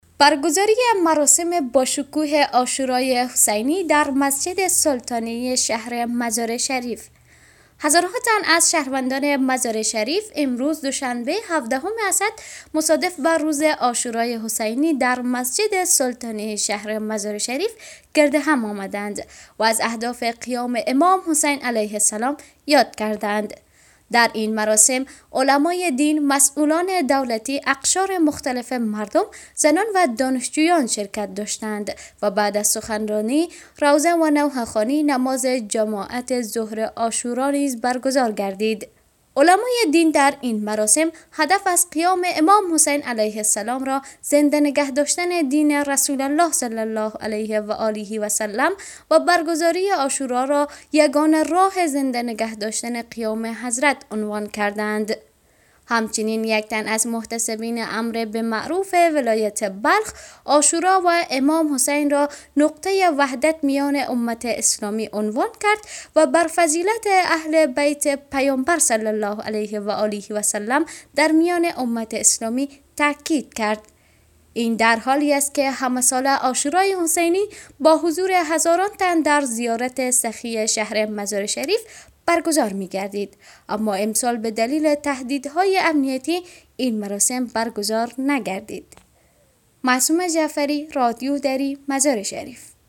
هزاران تن از شهروندان مزار شریف امروز دوشنبه (17 اسد) مصادف با روز عاشورای حسینی، در مسجد سلطانیه این شهر گرد آمدند و از اهداف قیام امام حسین علیه السلام ی...
در این مراسم علمای دین، مسئولان دولتی، اقشار مختلف مردم، زنان و دانشجویان شرکت داشتند و بعد از سخنرانی، روضه و نوحه خوانی، نماز جماعت ظهر عاشورا را برگزار کردند.